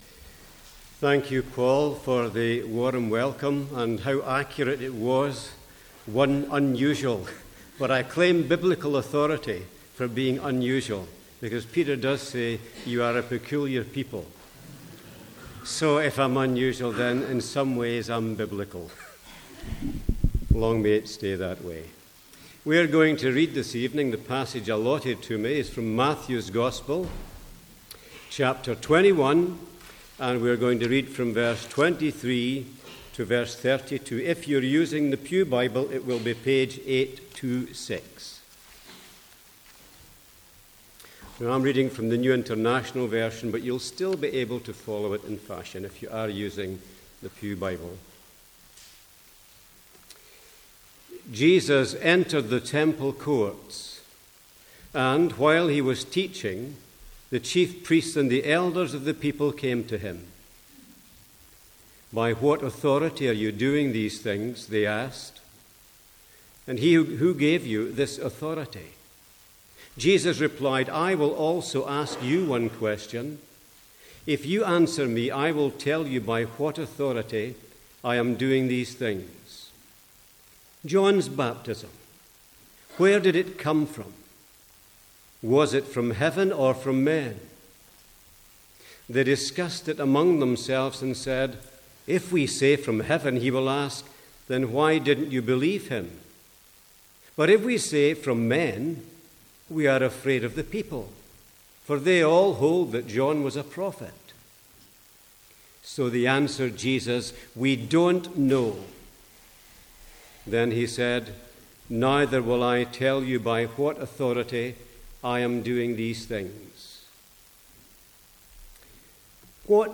Sermons | St Andrews Free Church
From the Sunday evening series in Matthew.